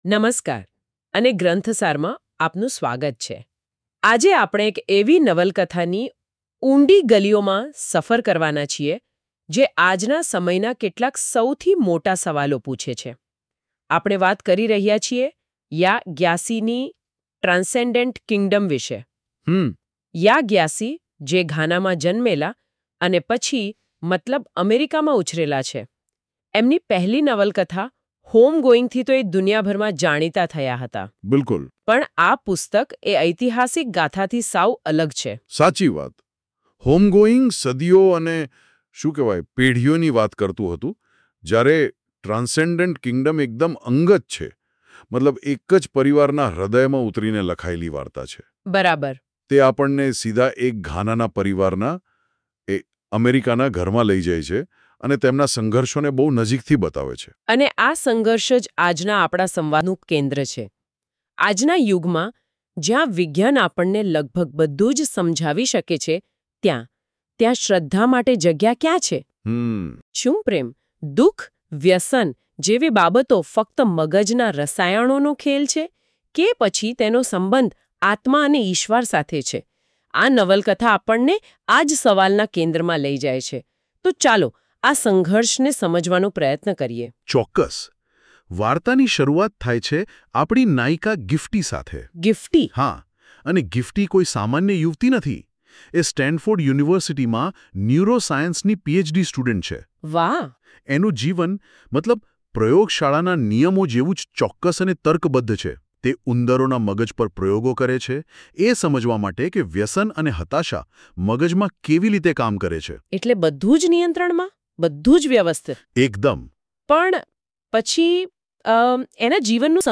Ekatra audio summary – Gujarati